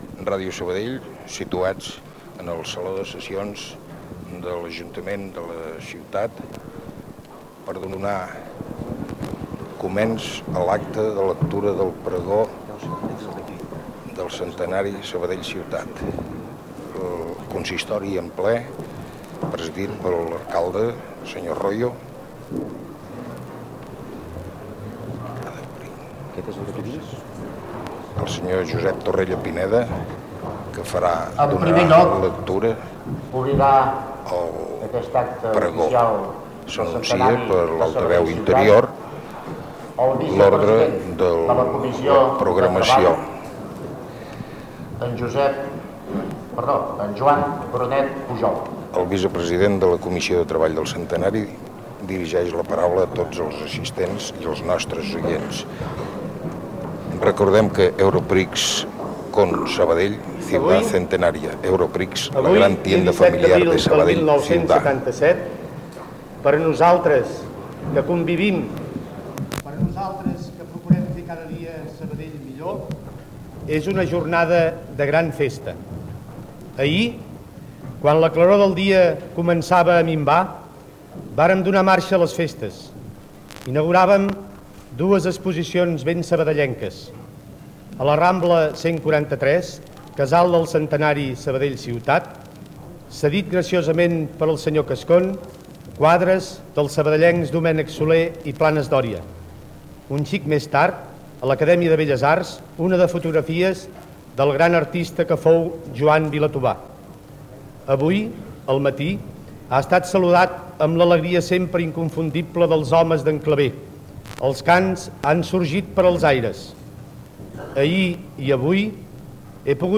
Transmissió des del saló de Sessions de l'Ajuntament de Sabadell en el dia del pregó del actes del Centenari de Sabadell Ciutat